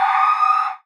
BWB THE WAVE VOX (15).wav